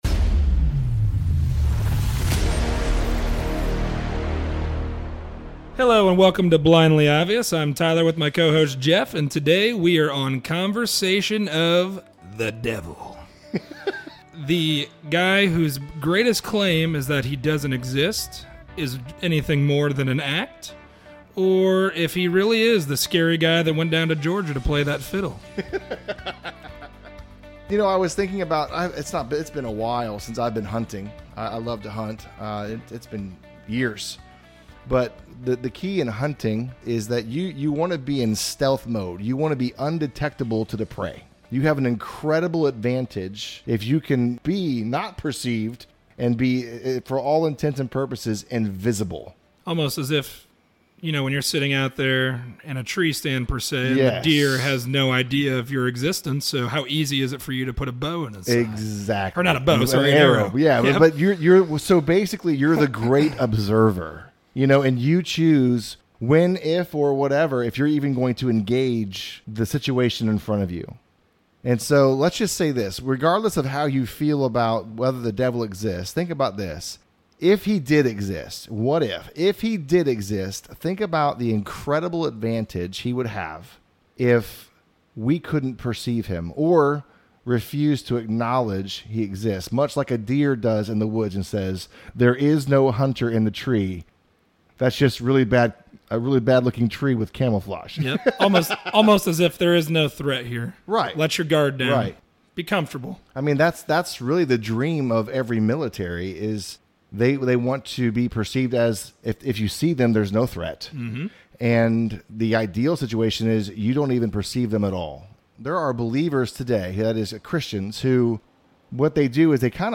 A conversation on the devil. A theoretical idea for evil, or as real as his counterpart for good?